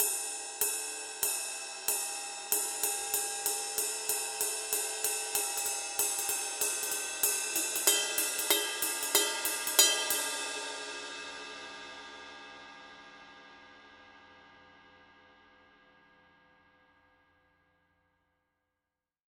20" Crash Ride Becken
RUDE continues to be the leading choice of sound for raw, merciless and powerful musical energy in Rock, Metal, and Punk.
20_ride-crash_pattern.mp3